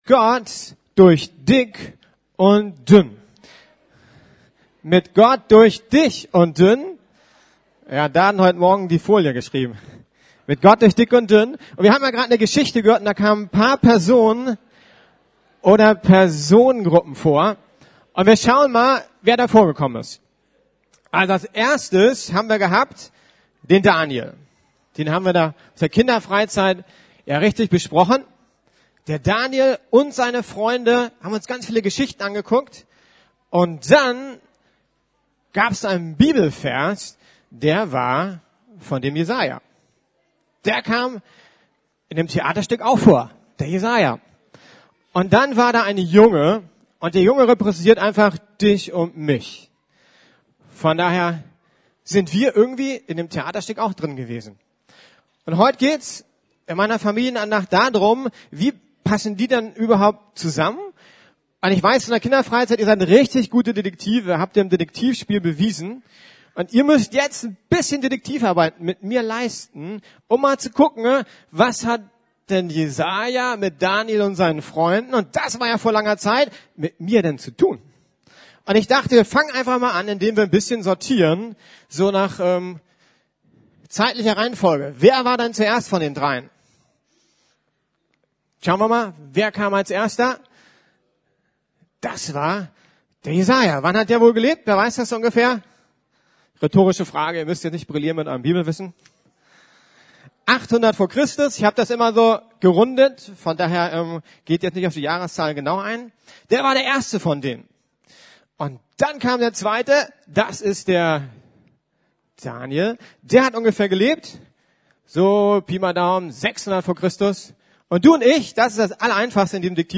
Mit Gott durch dick und dünn ~ Predigten der LUKAS GEMEINDE Podcast